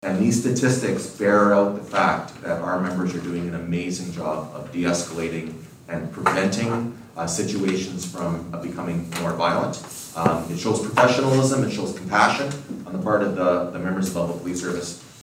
Mayor of Belleville Mitch Panciuk says residents should be proud of their police service.